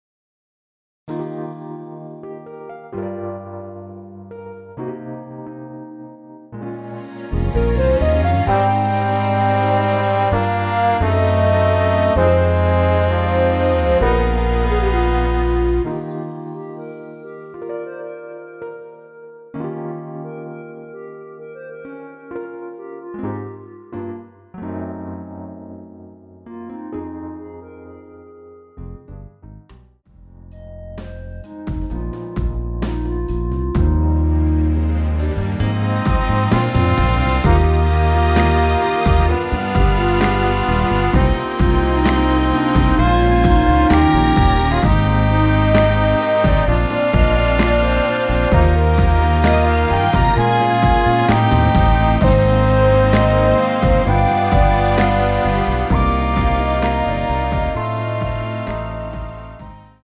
원키 멜로디 포함된 MR입니다.(미리듣기 참조)
Eb
앞부분30초, 뒷부분30초씩 편집해서 올려 드리고 있습니다.
중간에 음이 끈어지고 다시 나오는 이유는